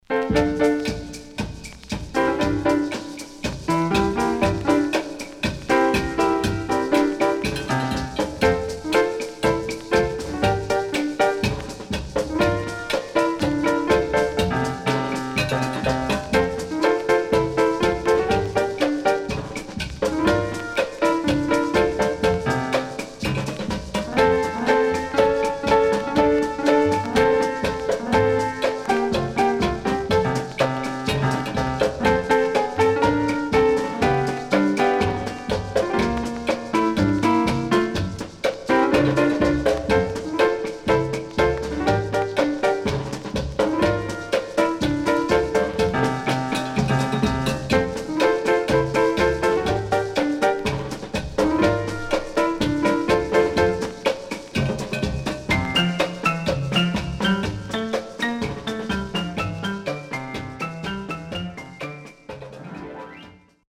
Rare.Jazzyかつ陽気なCarib Musicが堪能できる素晴らしいAlbum
SIDE A:全体的にノイズ入ります。